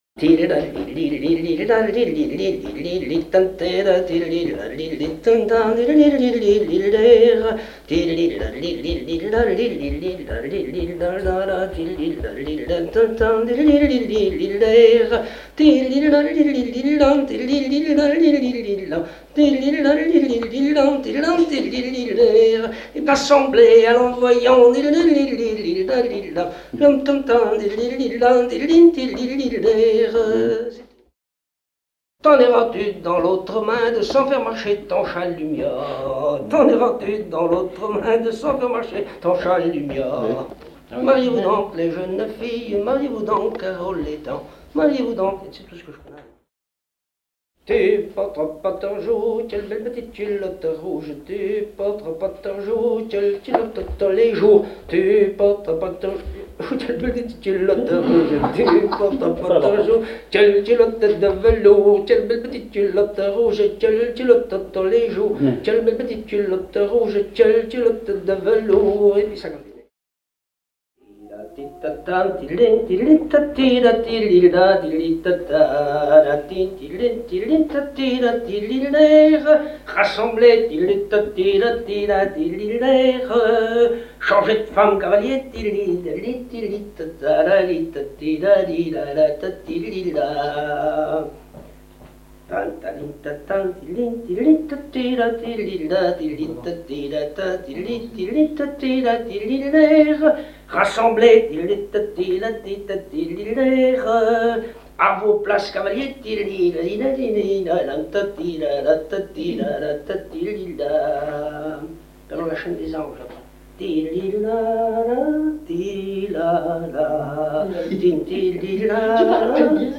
Rochetrejoux
danse : branle : avant-deux